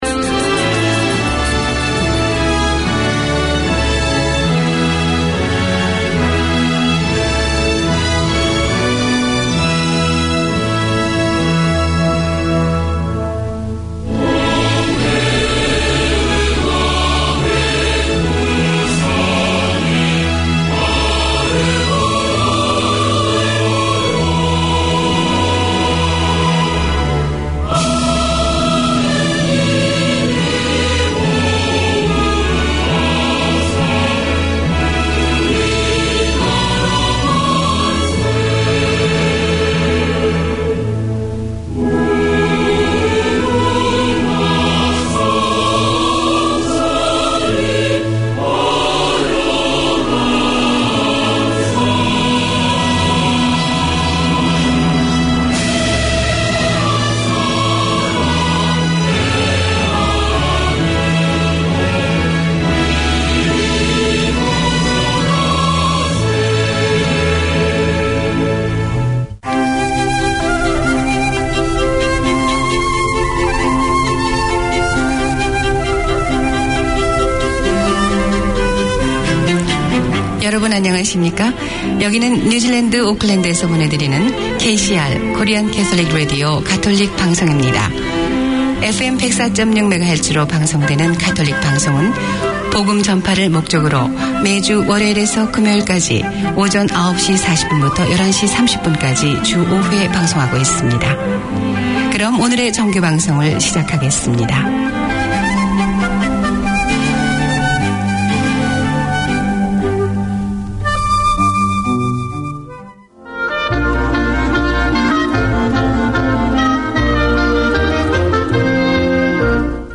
Community Access Radio in your language - available for download five minutes after broadcast.
A comprehensive arts show featuring news, reviews and interviews covering all ARTS platforms: film, theatre, dance, the visual arts, books, poetry, music ... anything that is creative.